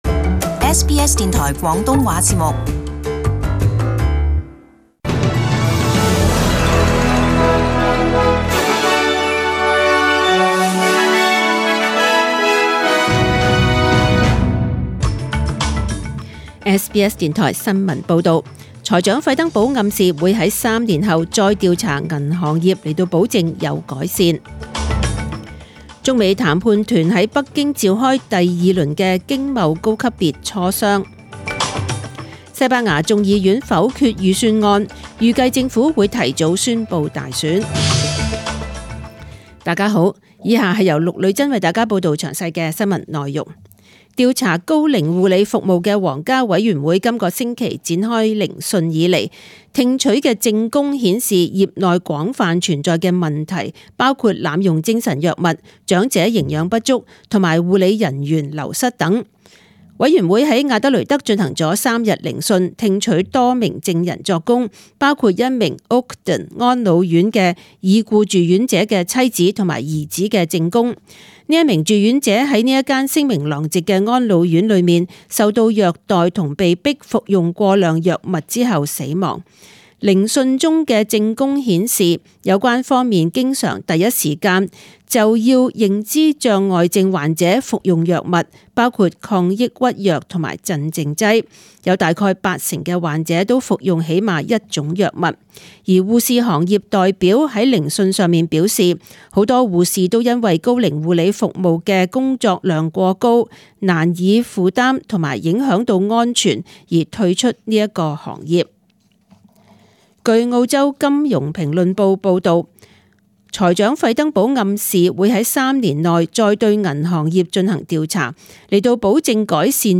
Detailed morning news bulletin
Chinese (Cantonese) News Source: SBS News